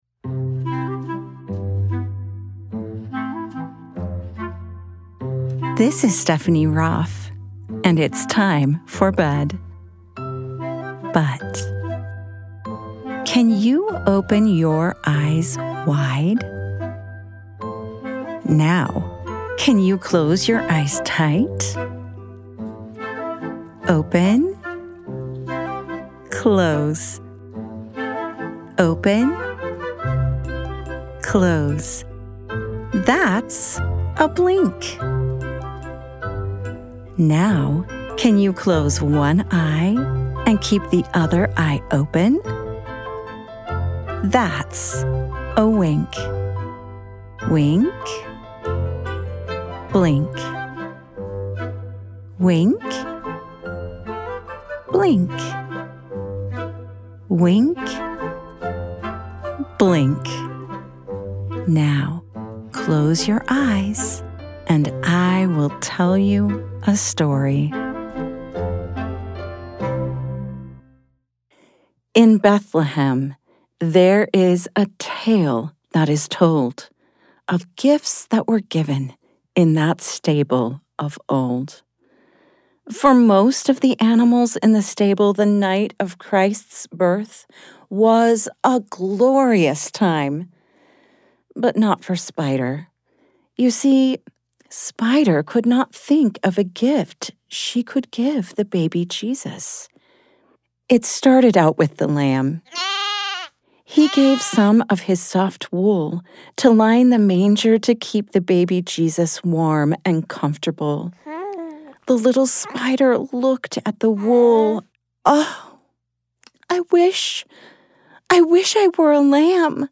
Spider's Gift: A Mindful Nativity Bedtime Story for Kids
@ wink-bedtime-stories Wink is a production of BYUradio and is always ad and interruption free.